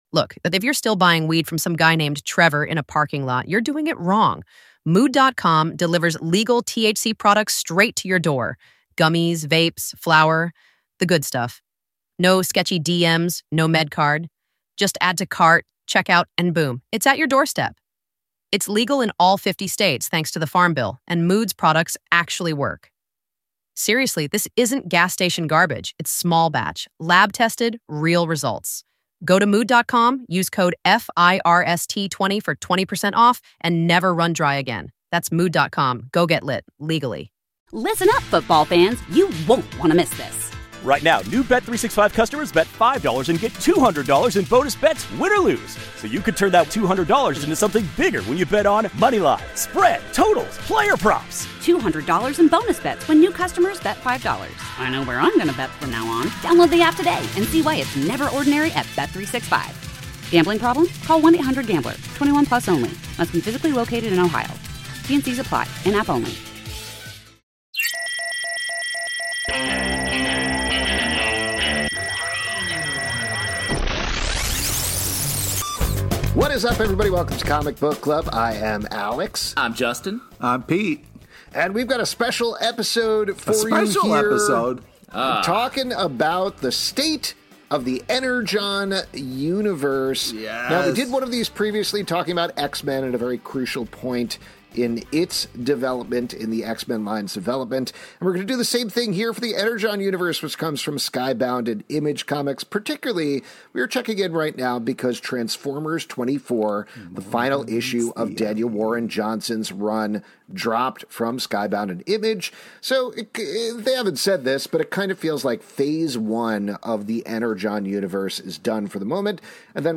On this week's live show